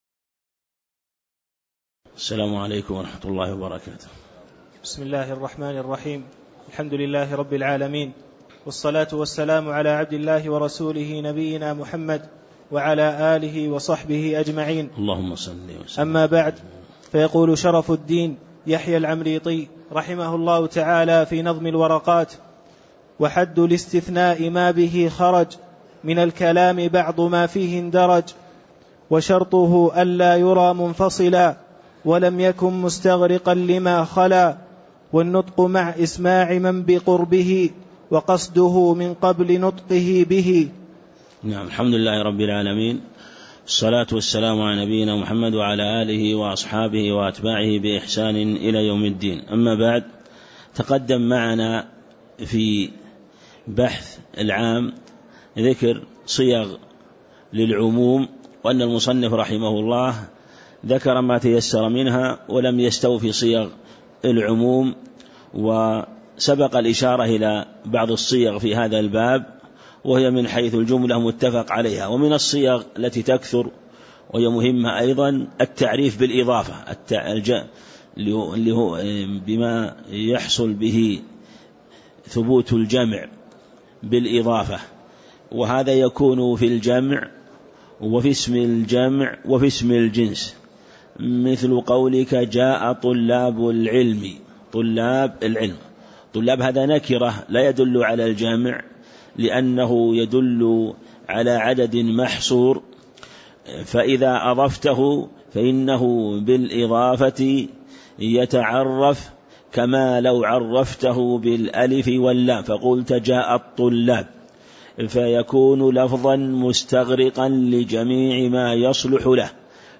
تاريخ النشر ١٥ شوال ١٤٣٦ هـ المكان: المسجد النبوي الشيخ